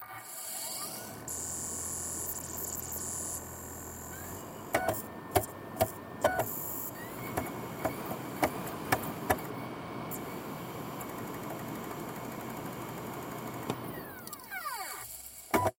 Звуки дисковода
Звук запуска дисковода при чтении DVD